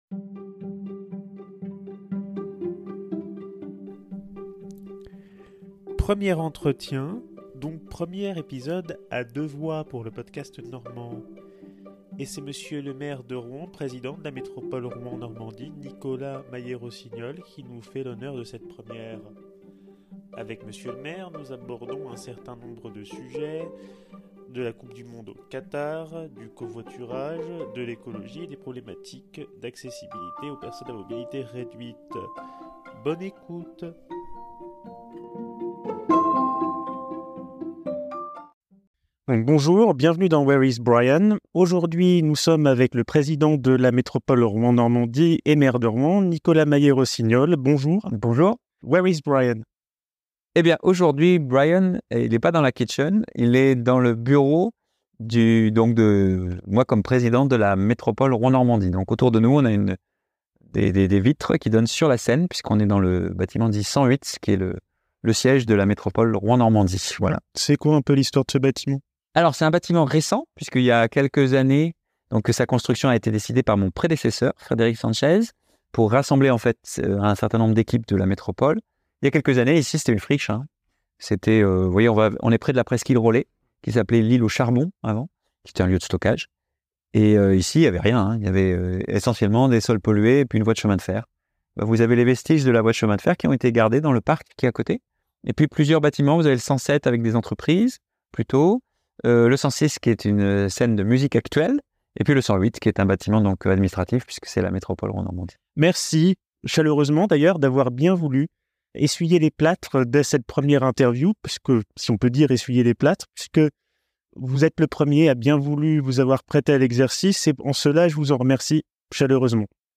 Handicap et territoire : un entretien avec le maire de Rouen, Nicolas Mayer-Rossignol